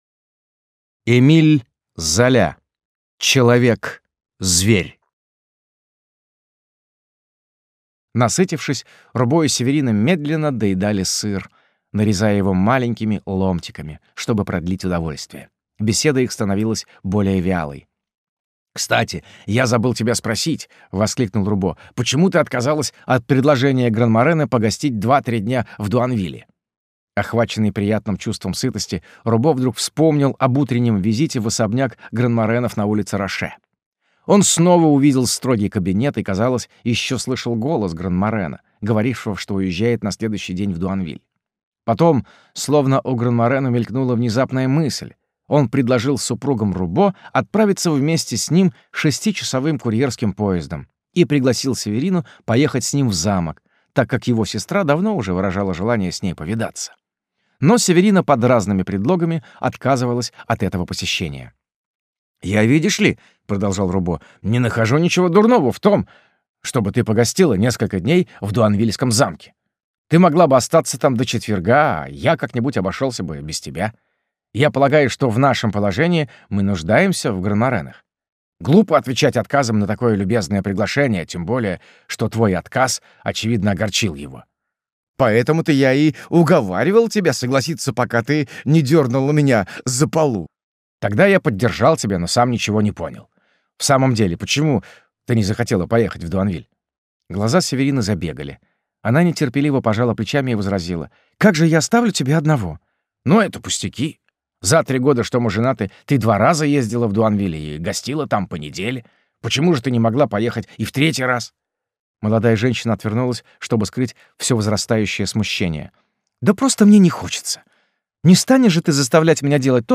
Аудиокнига Человек-зверь | Библиотека аудиокниг